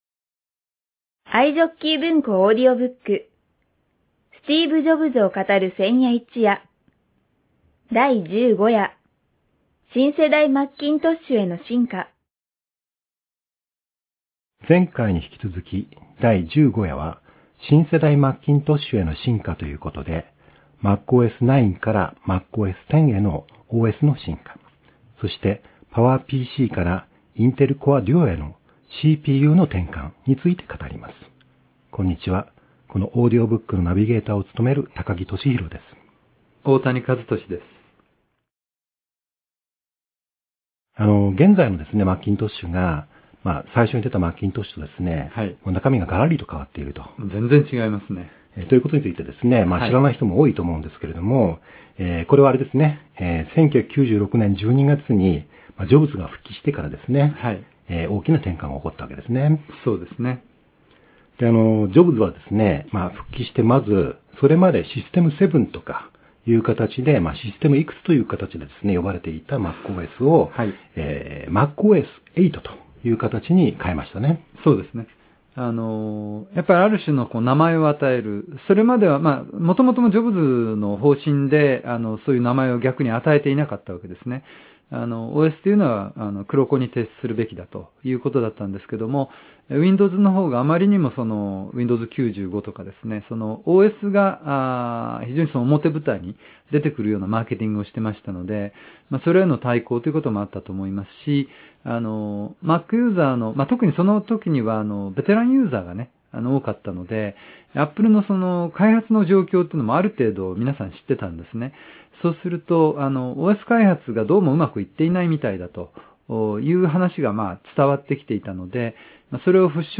[オーディオブック] スティーブ・ジョブズを語る千夜一夜 第15夜